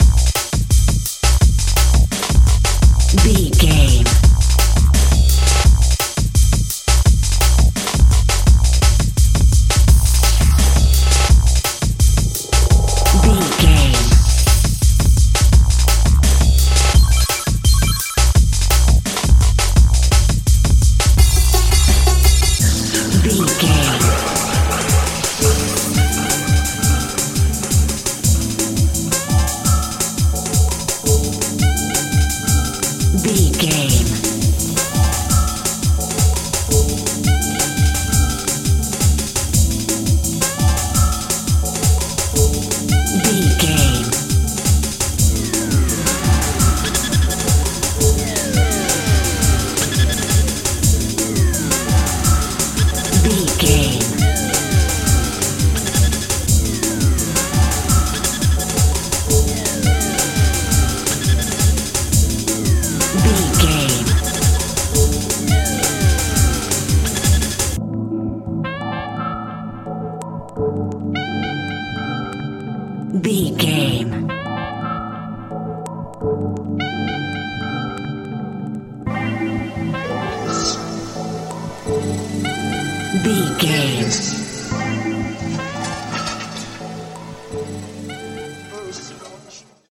Aeolian/Minor
Fast
drum machine
synthesiser
disco
Eurodance